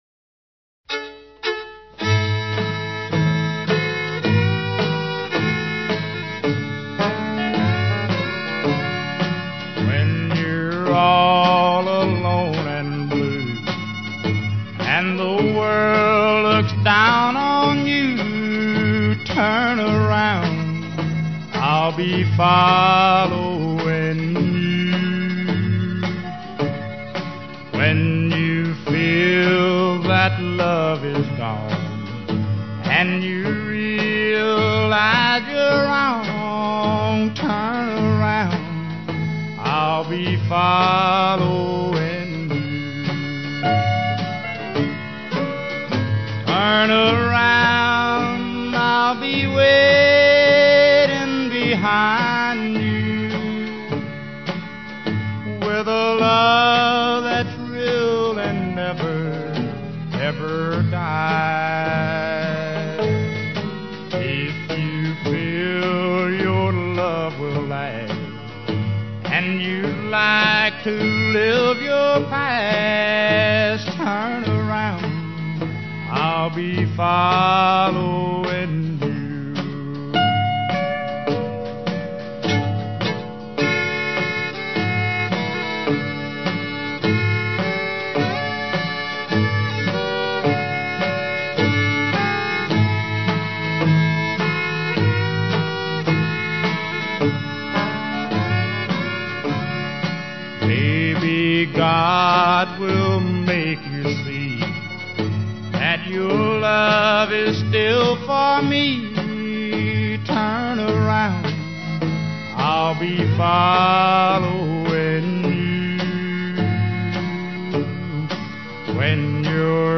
A Tribute To Old Time Country Music